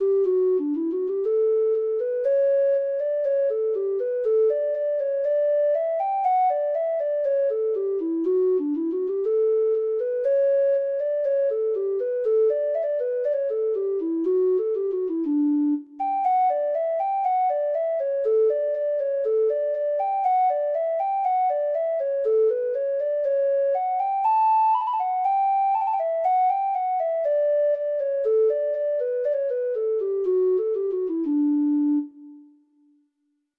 Free Sheet music for Treble Clef Instrument
Traditional Music of unknown author.
Reels
Irish